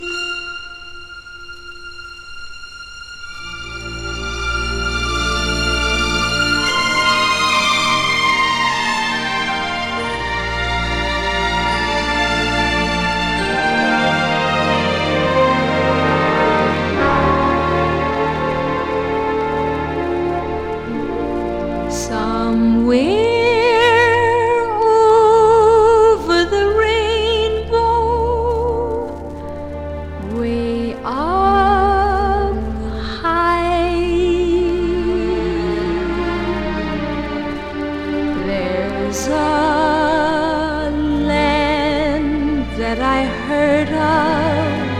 Pop, Vocal, Stage & Screen　USA　12inchレコード　33rpm　Stereo